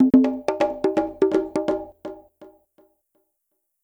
PERCUSSN028_DISCO_125_X_SC3(R).wav